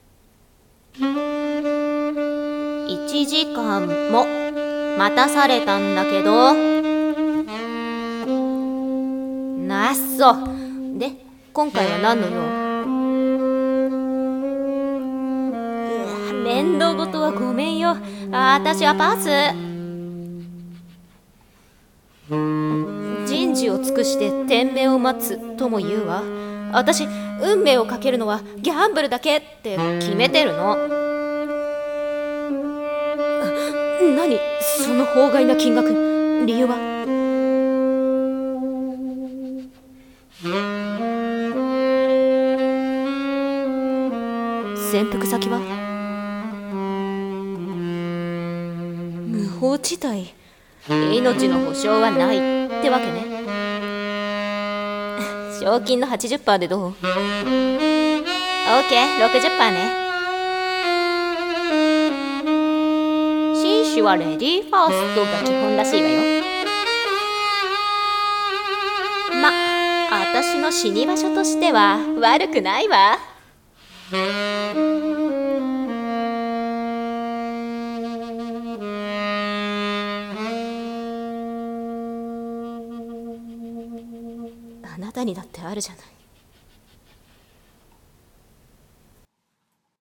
改【声劇】BOUNTY SOUL【2人声劇】